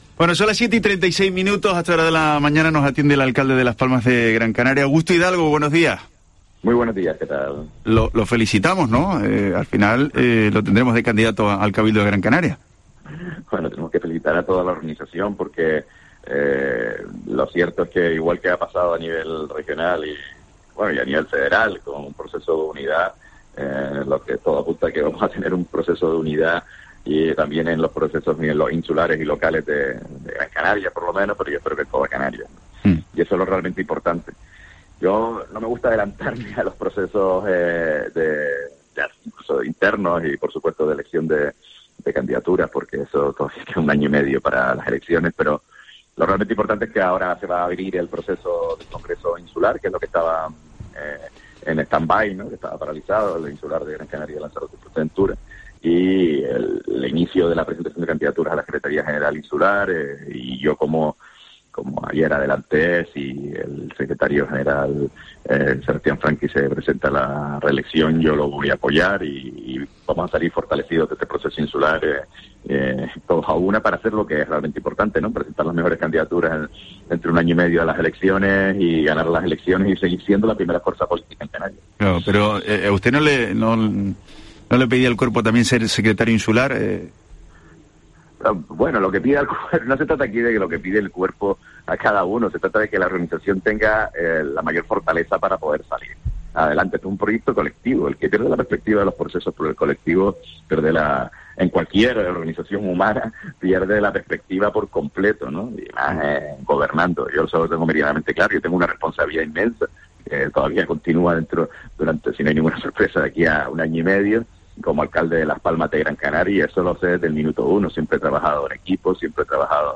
Augusto Hidalgo, alcalde de Las Palmas de Gran Canaria